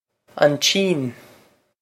an tSín on cheen
This is an approximate phonetic pronunciation of the phrase.